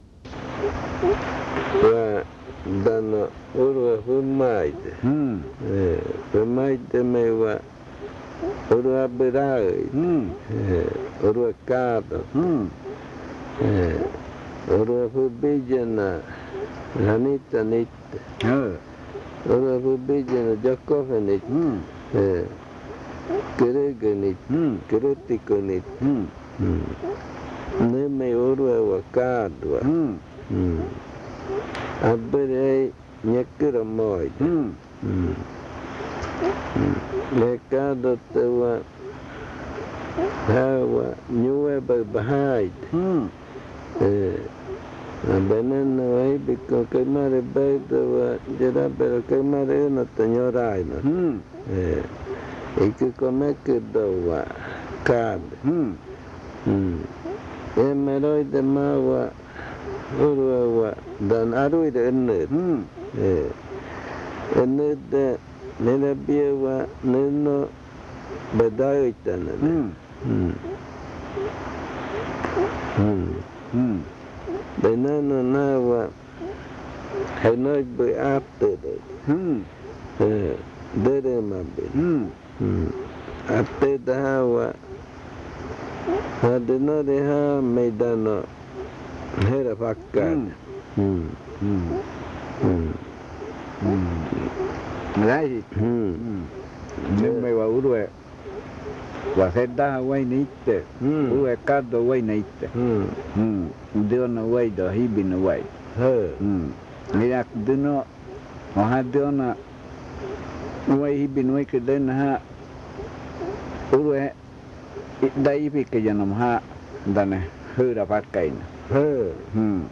Adofikɨ (Cordillera), río Igaraparaná, Amazonas
Esta grabación contiene la explicación y el canto de una conjuración para el niño que tiene inquietud.